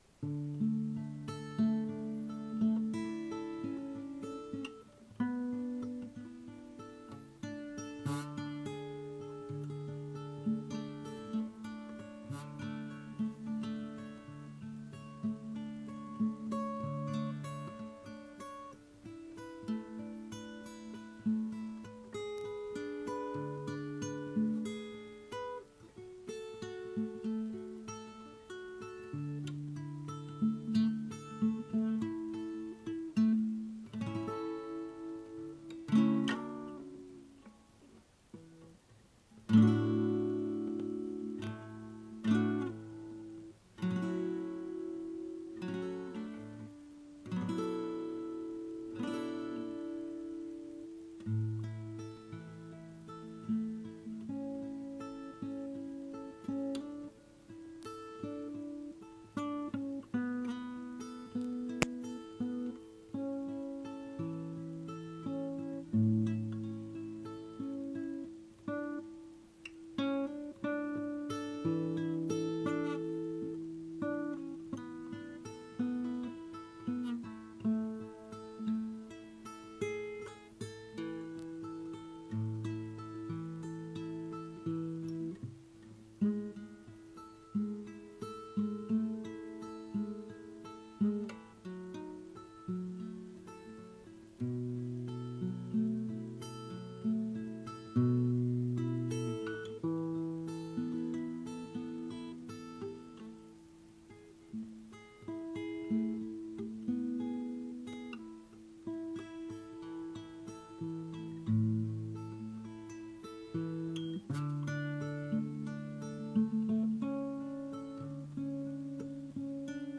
5 minutes of imperfect soaking music.